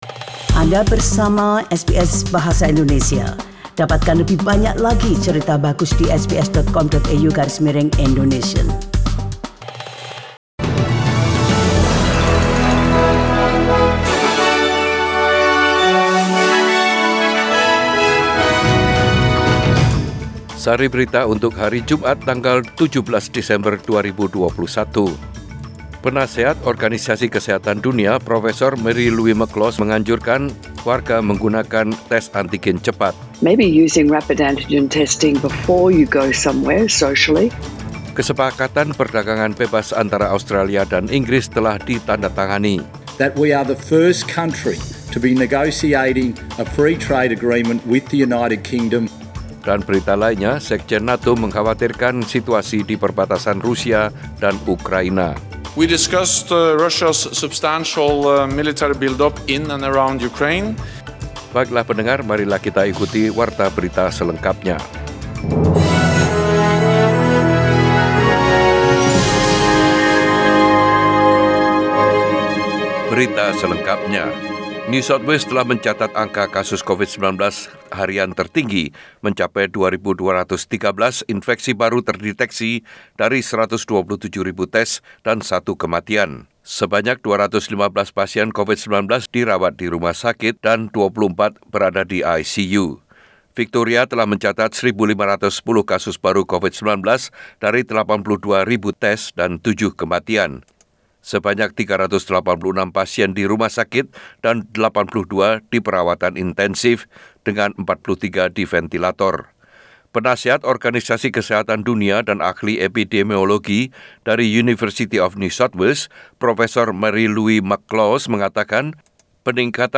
SBS Radio News in Bahasa Indonesia - 17 December 2021
Warta Berita Radio SBS Program Bahasa Indonesia.